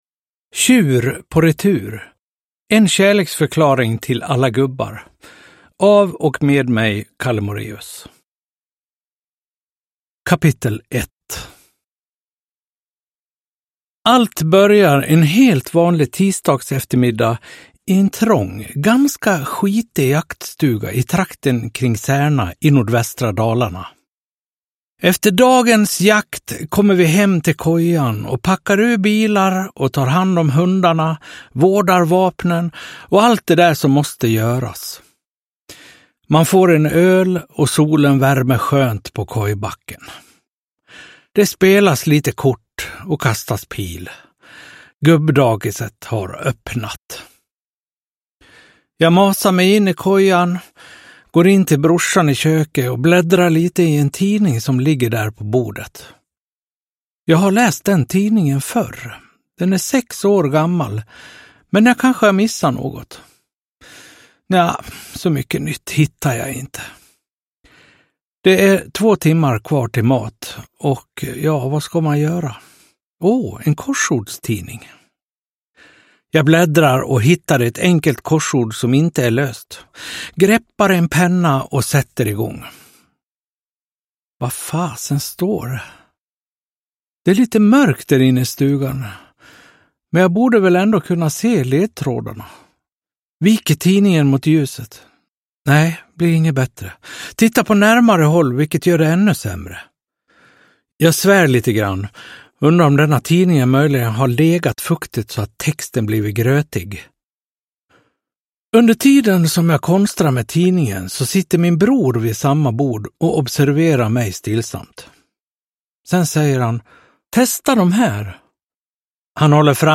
Tjur på retur : en kärleksförklaring till alla gubbar – Ljudbok – Laddas ner
Uppläsare: Kalle Moraeus